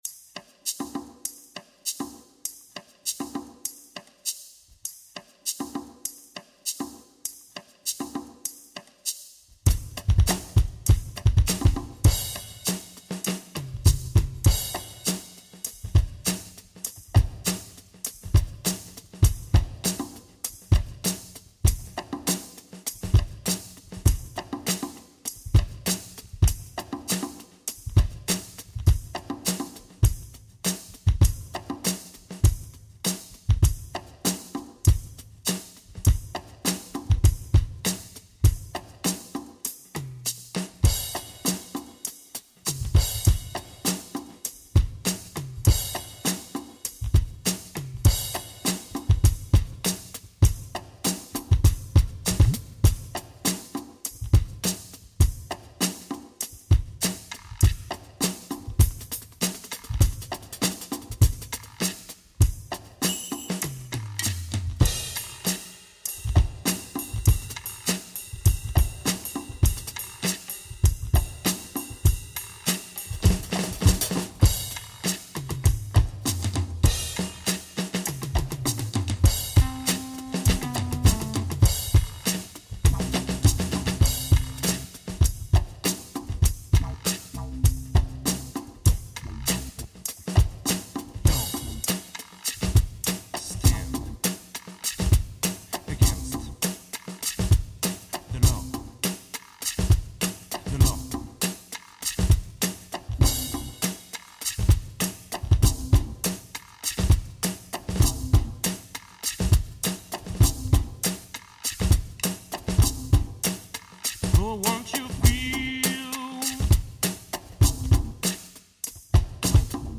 Synthèse de 28 minutes d'improvisation progressive , ou comment se prendre pour Jesto-funk ou Zappa alors qu'on est que 2 à jouer.